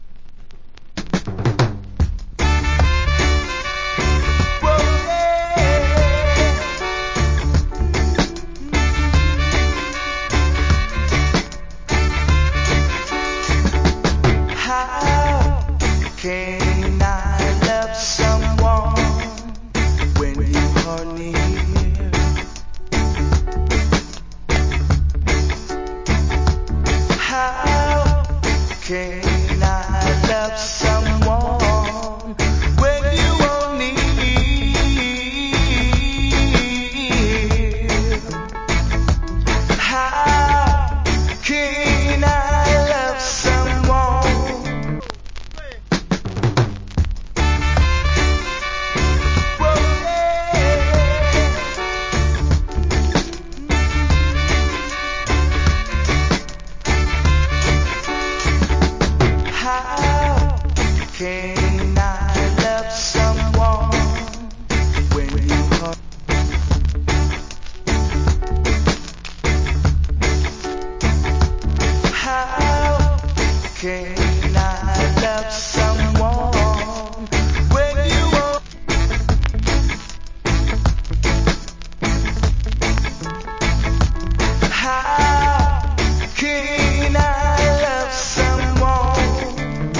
Good Vocal.